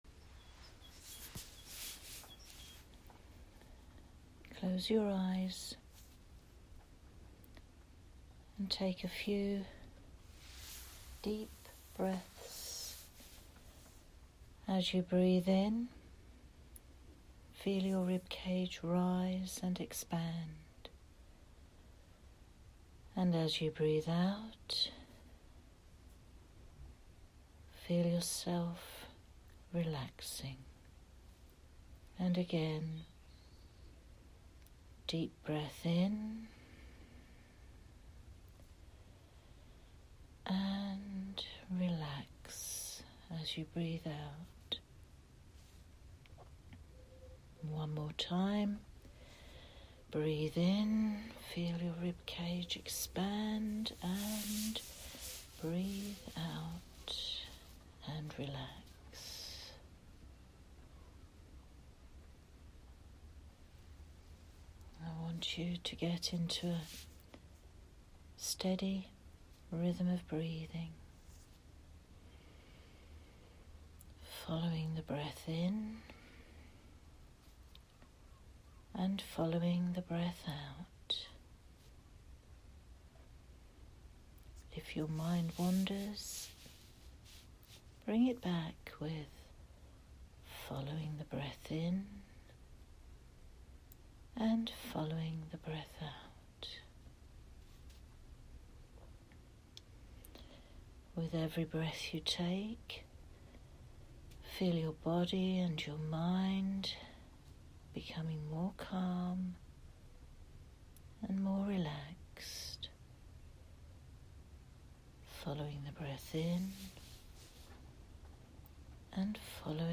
Grounding Meditation